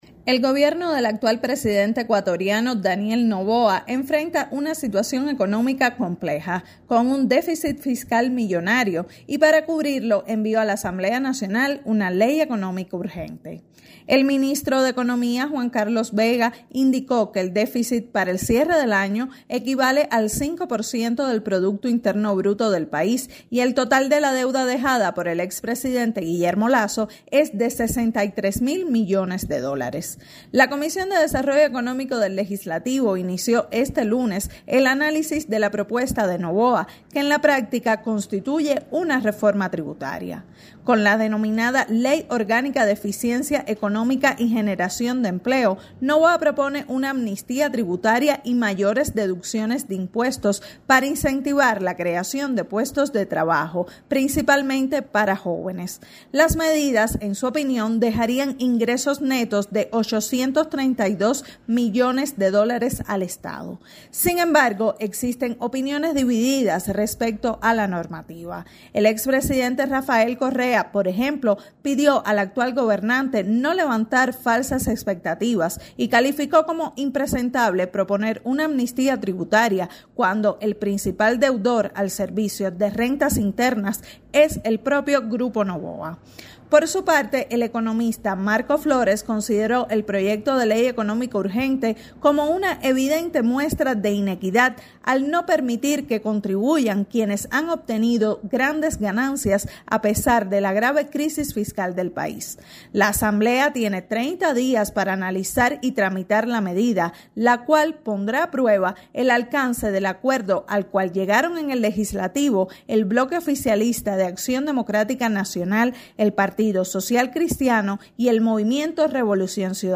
desde Quito